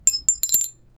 Royalty-free break sound effects
glass fall
glass-fall-vrnyr7lw.wav